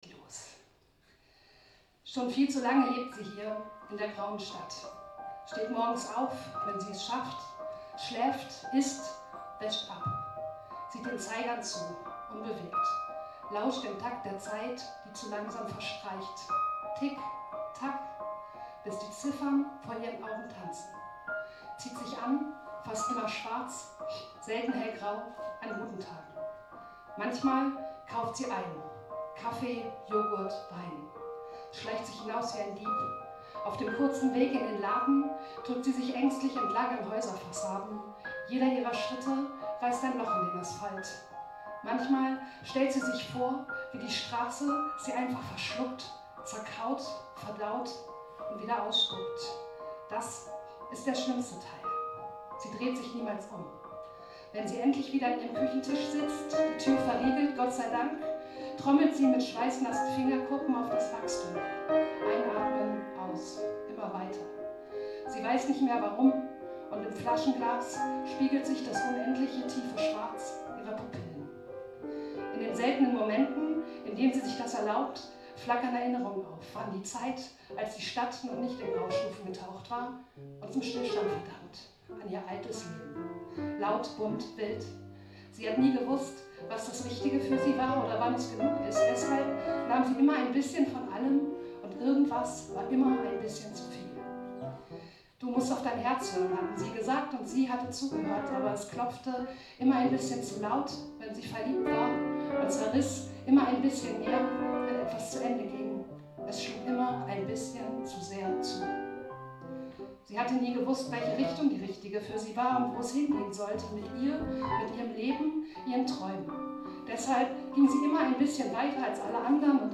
Unlängst hatte ich aber die große Freude, den mir sehr am Herzen liegenden Text „Die graue Stadt“ beim Wordka in Görlitz zu lesen, als dort ein Jazz Spezial zelebriert wurde.
Wie immer natürlich improvisiert und mit nur sehr kurzer Vorbesprechung.